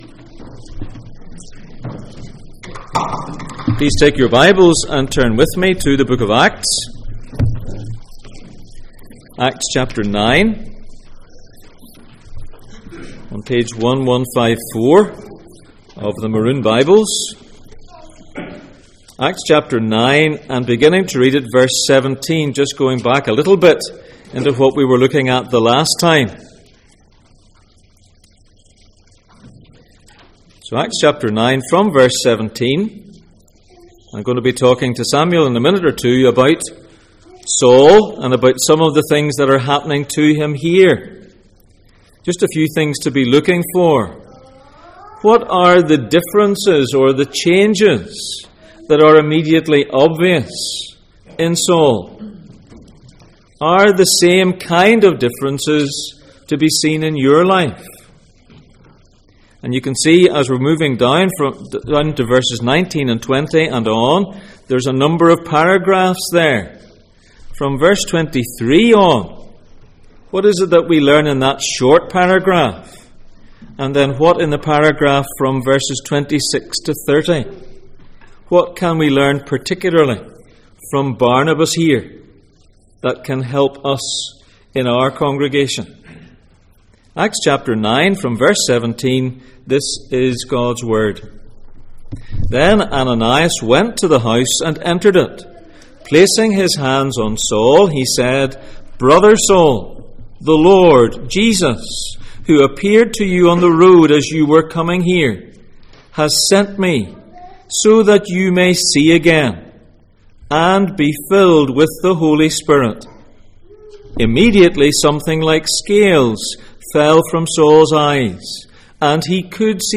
The Acts of the Reigning Jesus Passage: Acts 9:17-30, 2 Kings 18:5-6 Service Type: Sunday Morning